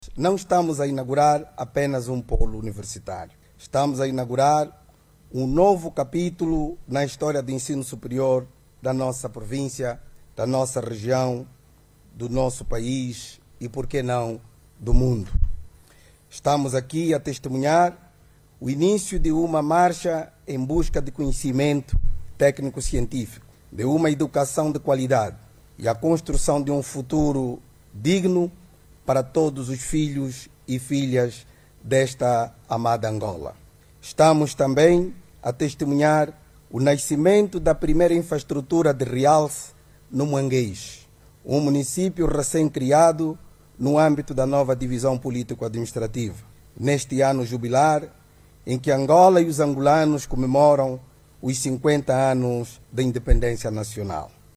Daniel Félix fez estas declarações na manhã desta quinta-feira, momentos depois de o Presidente da República, João Lourenço, inaugurar o Instituto Politécnico da Universidade Lueji A’Nkonde.